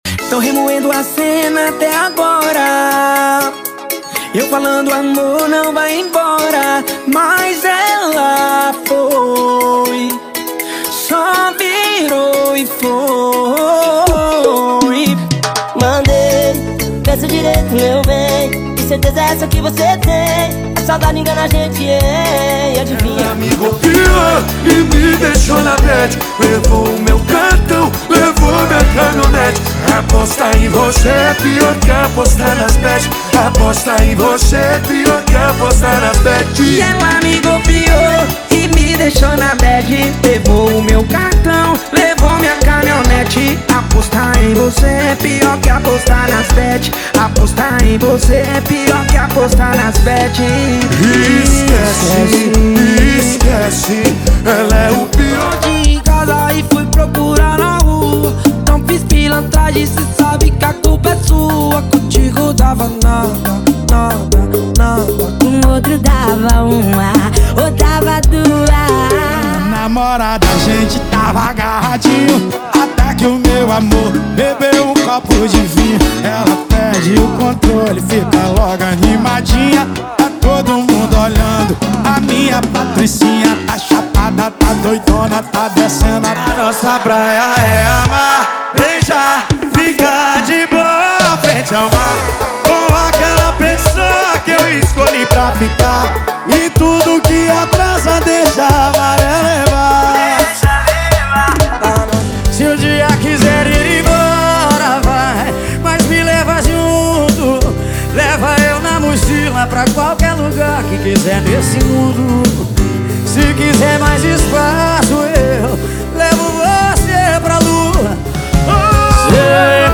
• Sertanejo = 65 Músicas
• Sem Vinhetas
• Em Alta Qualidade